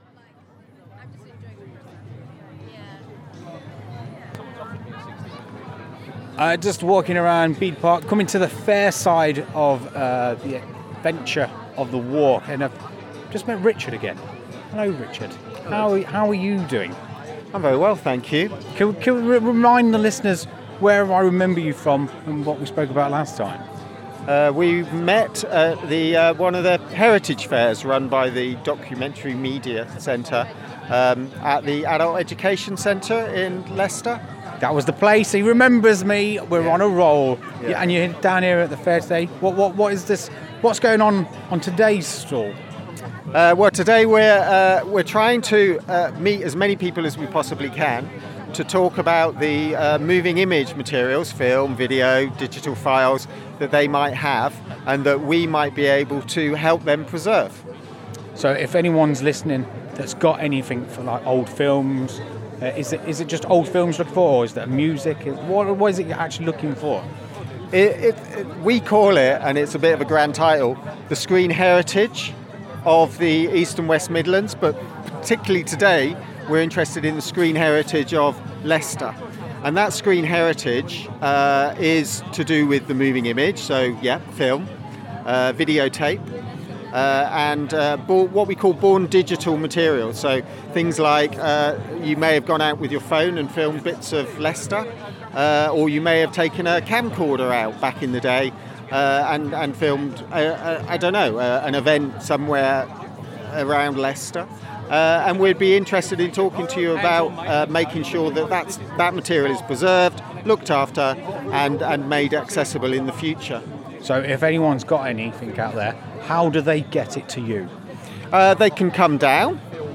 Soar Sound at Riverside Festival 2024 – Soar Sound